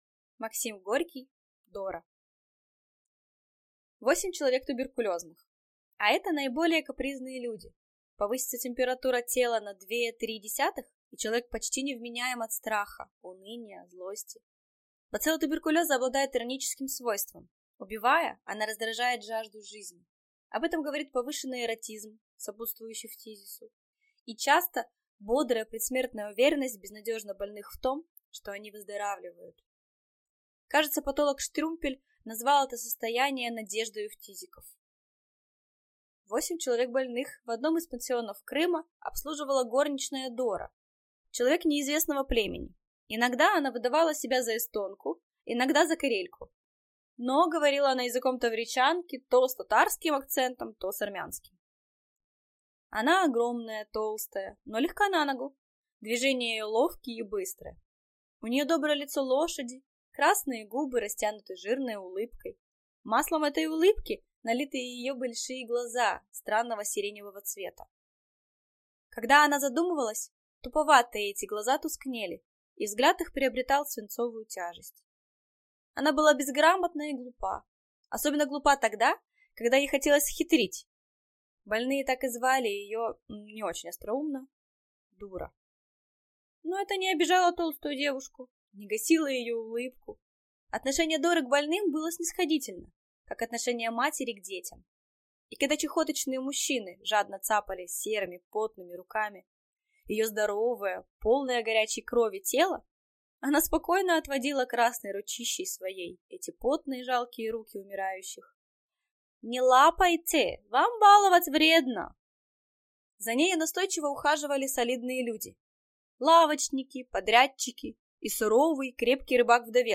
Аудиокнига Дора | Библиотека аудиокниг
Aудиокнига Дора Автор Максим Горький